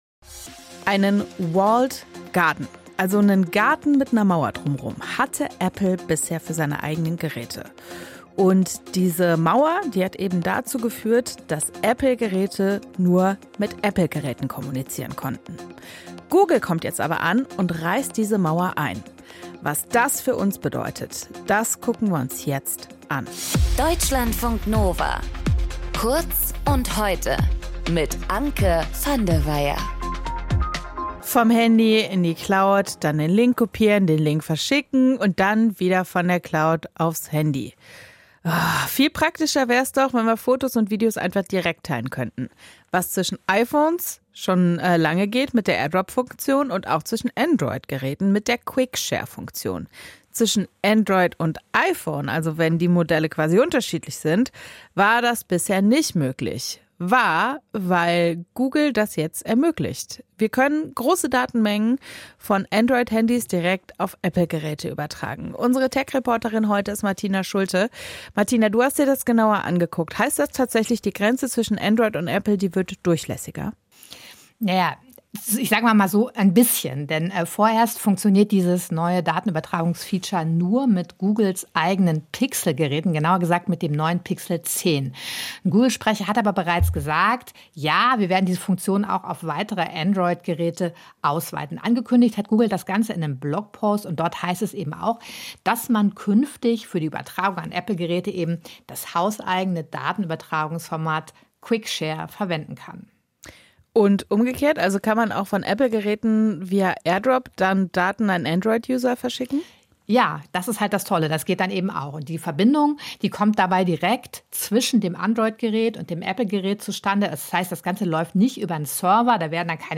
Moderatorin: